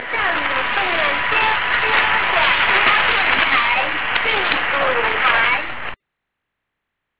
Each channel identifies itself at the beginning of the broadcasts as "the third" or "the fourth" program.